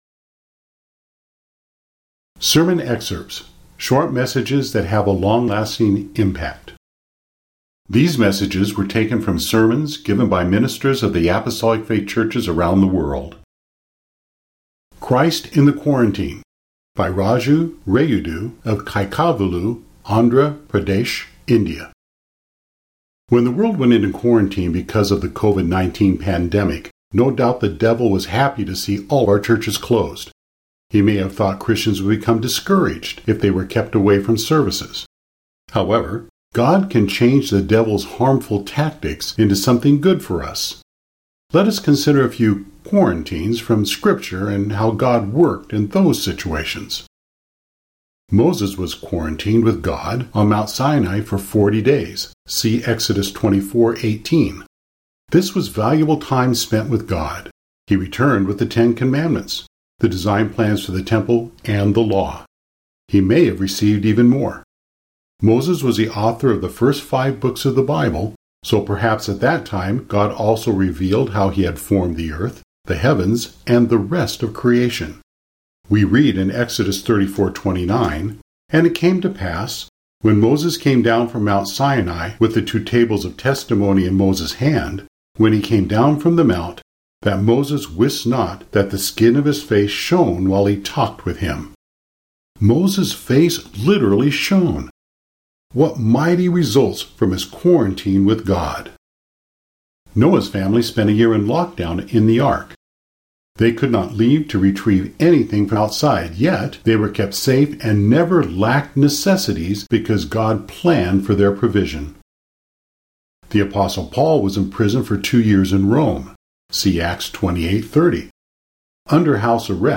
Sermon Excerpts